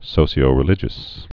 (sōsē-ō-rĭ-lĭjəs, -shē-)